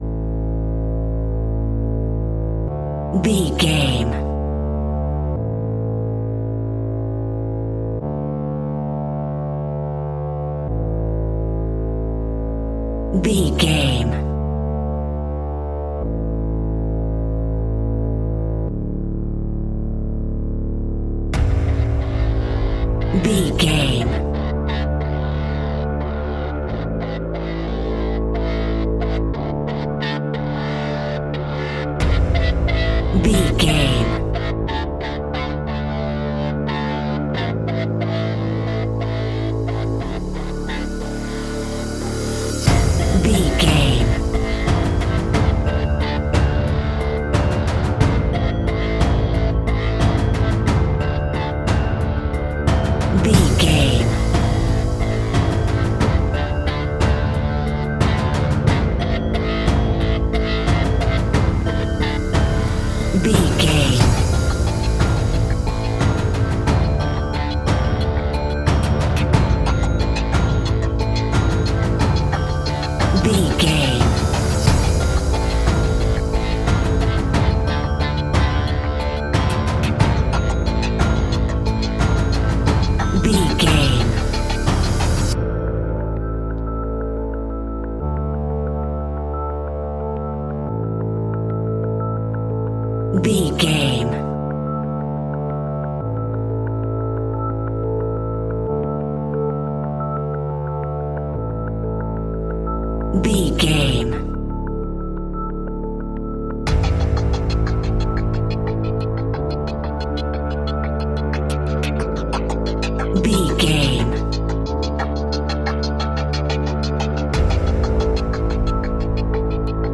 A great piece of royalty free music
Aeolian/Minor
D
scary
tension
ominous
dark
haunting
eerie
synthesiser
drums
ticking
electronic music